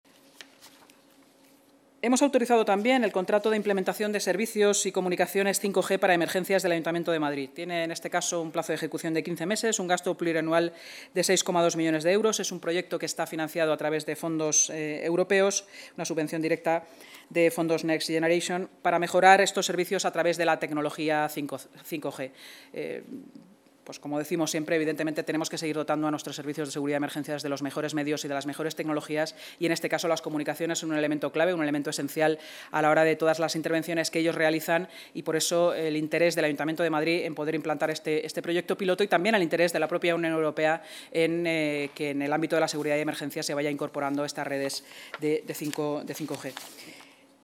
Nueva ventana:Intervención de Inma Sanz, vicealcaldesa, portavoz municipal y delegada de Seguridad y Emergencias, en la rueda de prensa posterior a la Junta de Gobierno